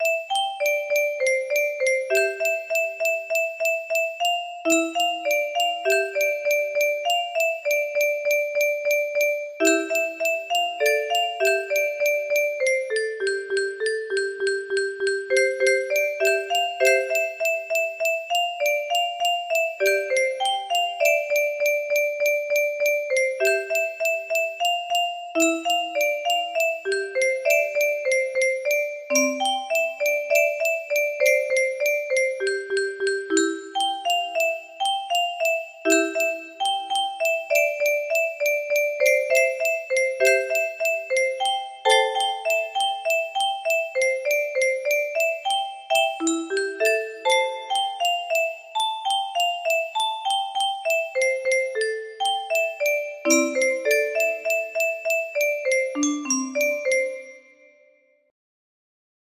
Clone of Leo shorter music box melody
Wow! It seems like this melody can be played offline on a 15 note paper strip music box!